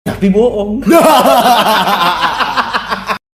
Efek Suara Tapi Boong hahaha…
Kategori: Suara ketawa
Keterangan: Efek suara "Tapi Boong hahaha..." viral di Indonesia, cocok buat meme lucu dan edit video.
efek-suara-tapi-boong-hahaha-id-www_tiengdong_com.mp3